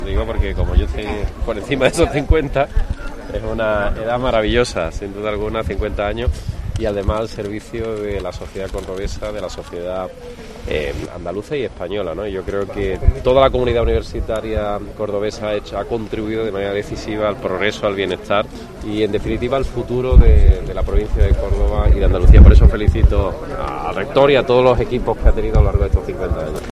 Escucha a Juanma Moreno en la celebración de los 50 años de la UCO